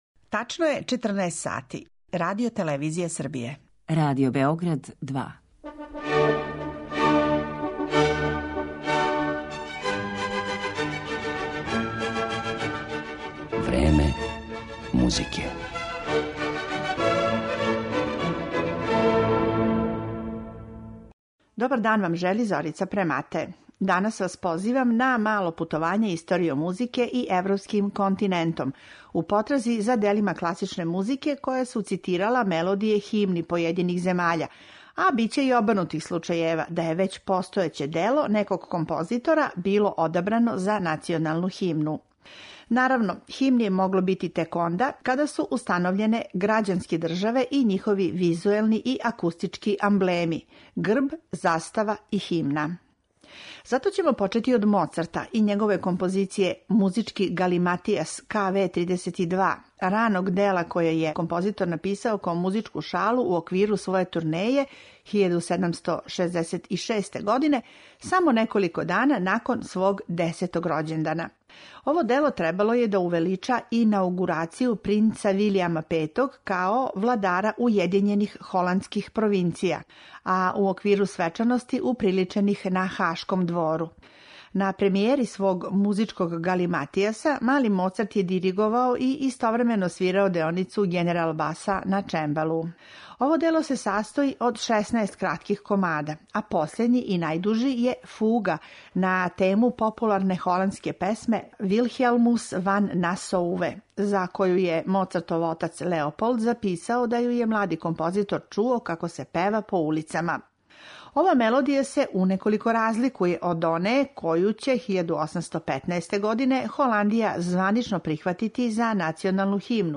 Слушаћете дела класичне музике која су цитирала мелодије химни појединих земаља и дела композитора која су била одабрана за националну химну.